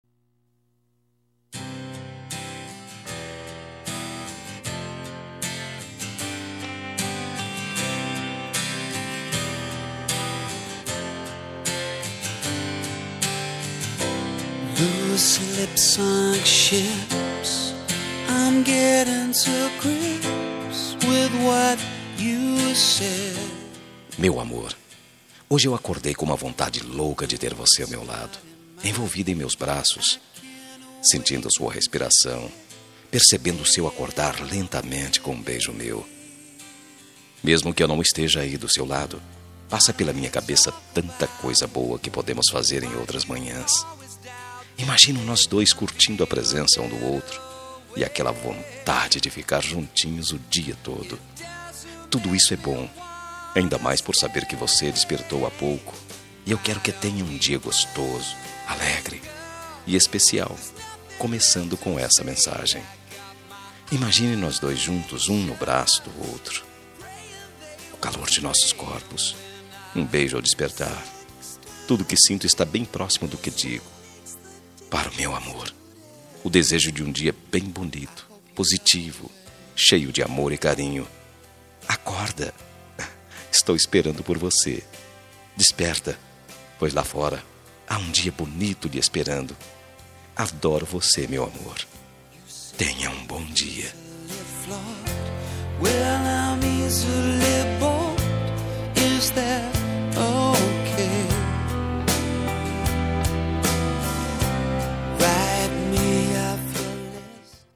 Telemensagem de Bom dia – Voz Masculina – Cód: 6323 – Romântica
6323-bom-dia-masc.mp3